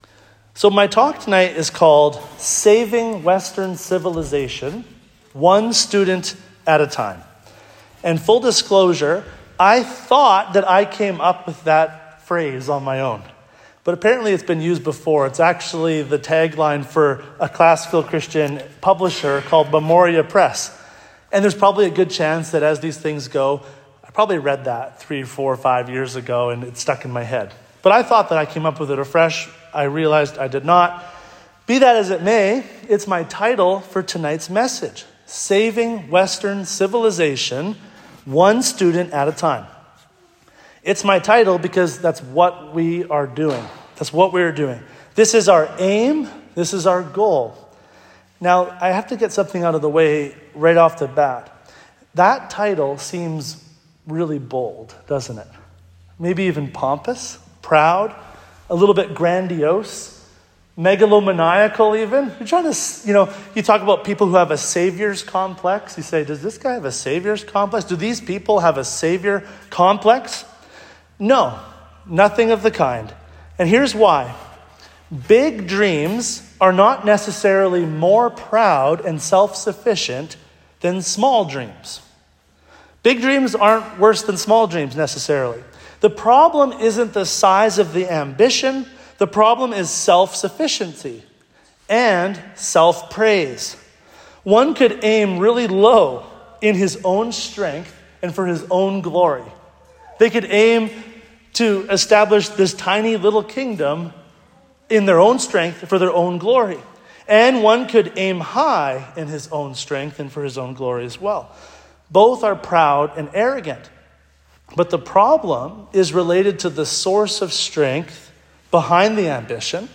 [These are the notes from the talk given at a Redeemer Classical Academy Open House on March 14th, 2025.